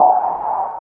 51 WRD CYM-L.wav